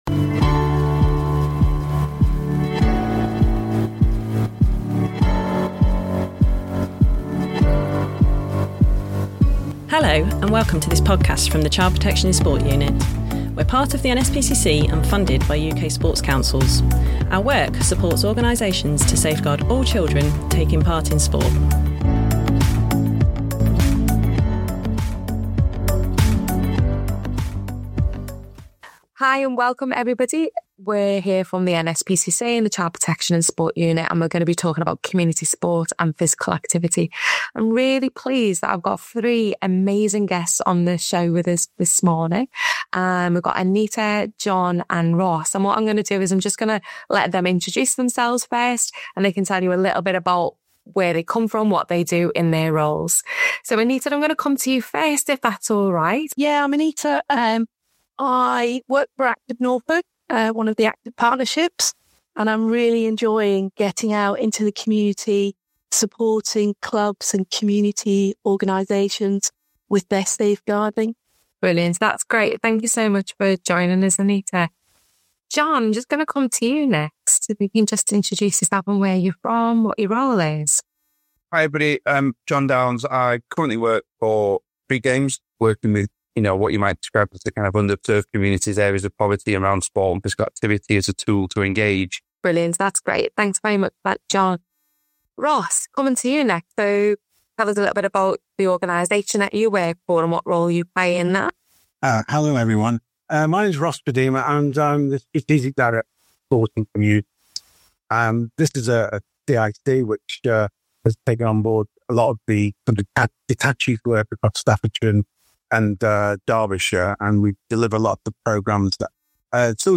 Dive into our latest podcast where we explore the vital role of safeguarding in community sports and physical activities. The discussion features three guests